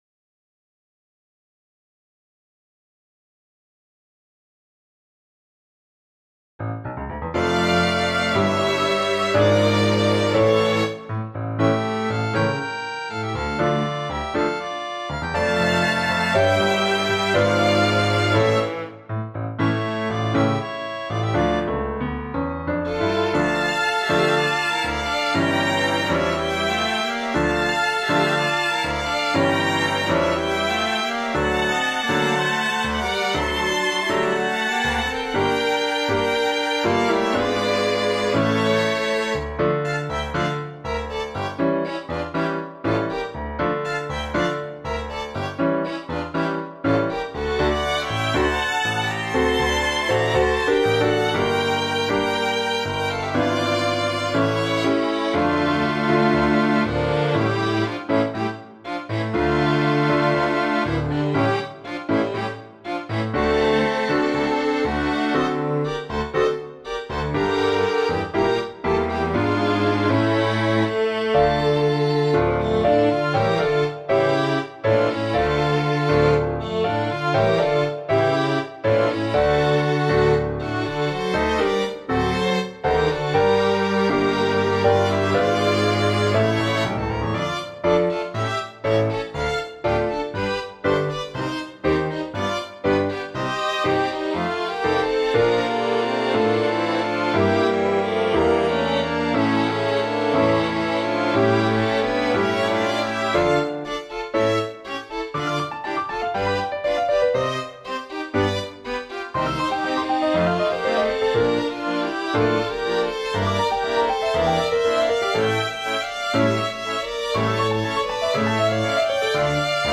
clapas-tango-musescore.mp3